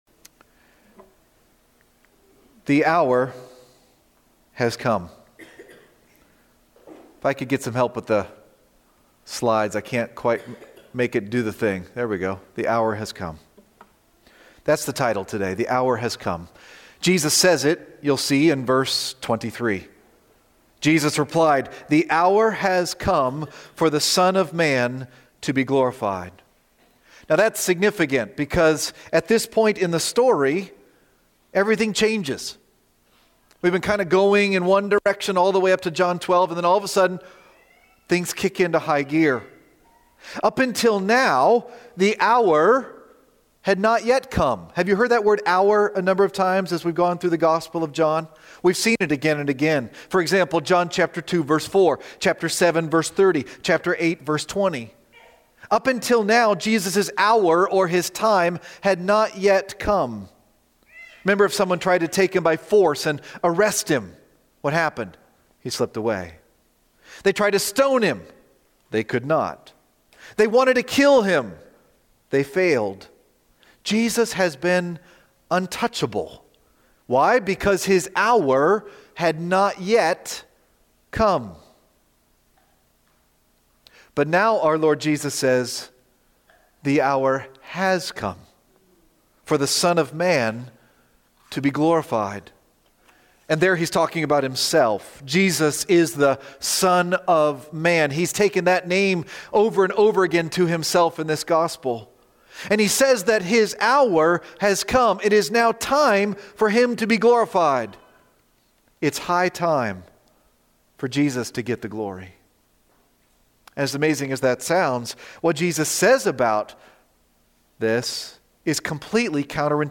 preaching on John 12:12-26